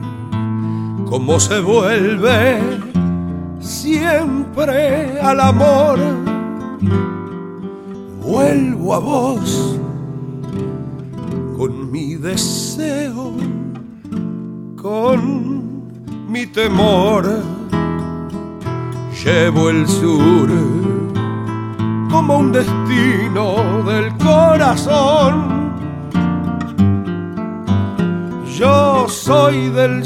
voix
harmonica
guitare